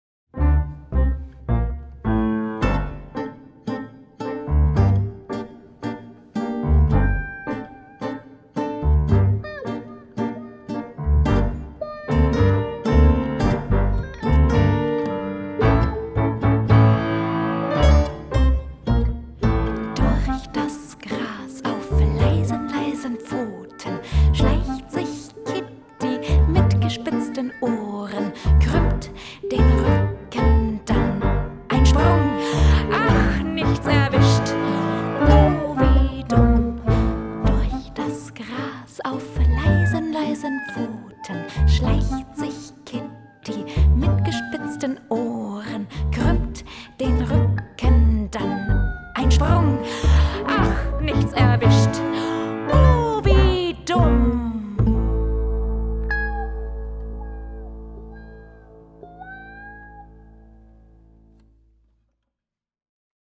Einspielung des Sprechstücks